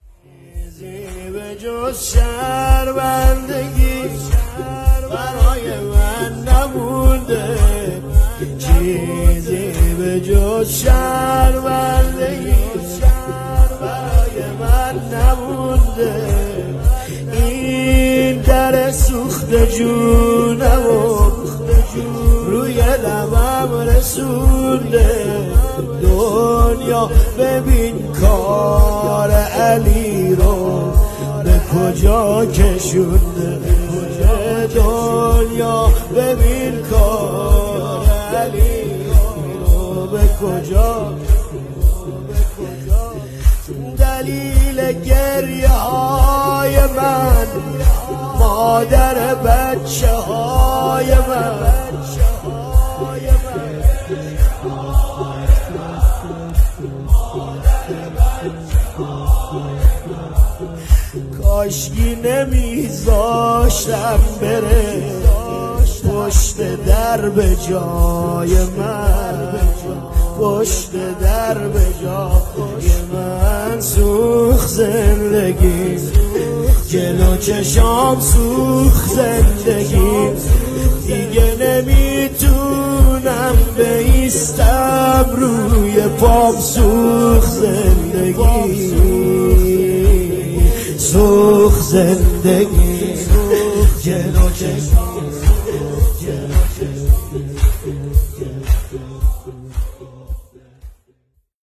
نوحه ایام فاطمیه شور